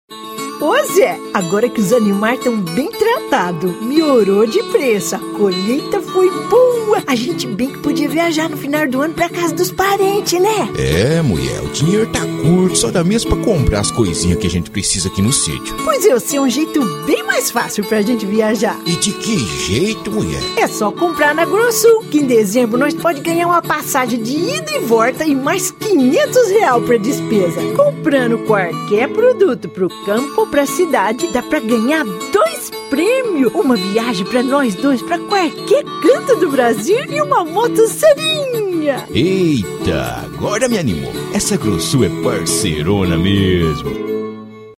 Sprecherin brasilianisch.
Kein Dialekt
Sprechprobe: Sonstiges (Muttersprache):
female brazilian voice over.